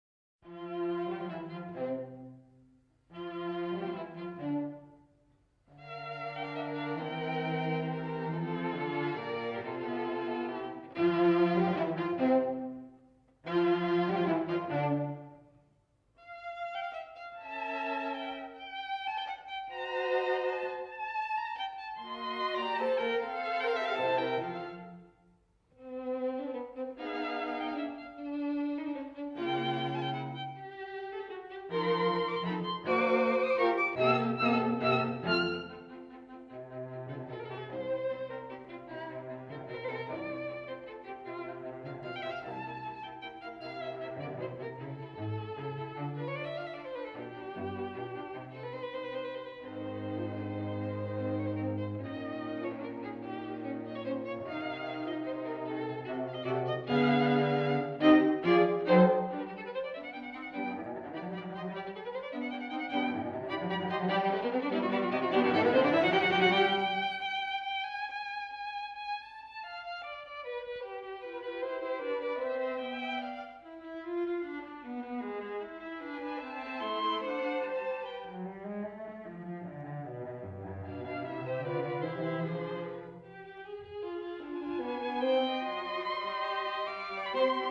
* Ludwig van Beethoven – String quartet op.18 no. 1